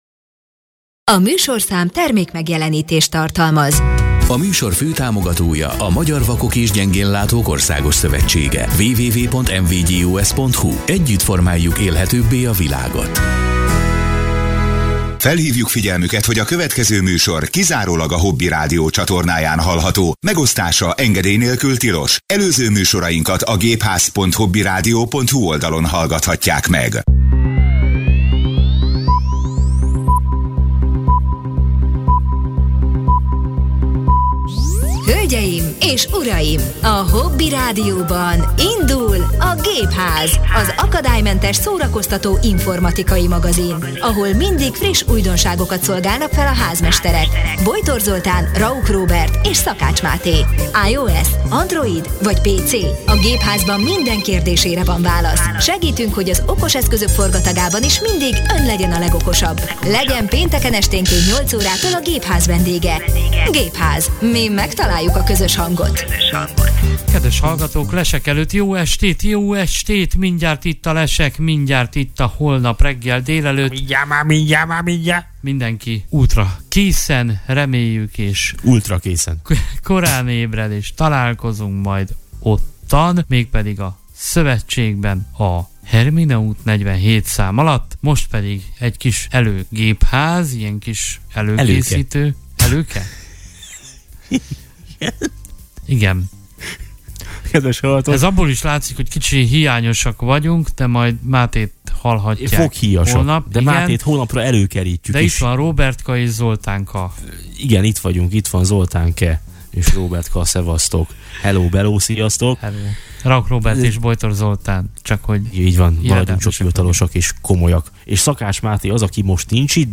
Péntek esti műsorunkban hallgatóink leveleit sorvezetőnek használva beszélgettünk egy jót.